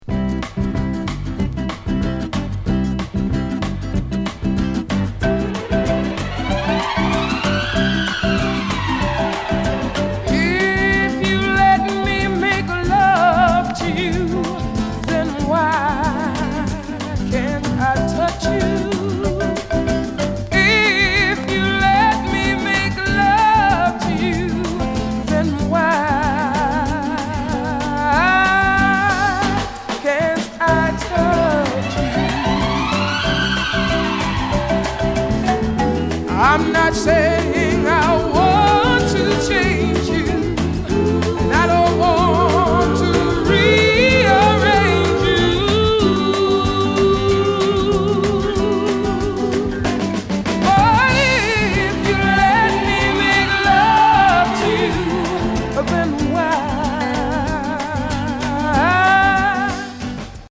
軽快なリムショットに爽やかなギターや木琴、雄大な雰囲気を持ったソフト・ロック調の1曲です。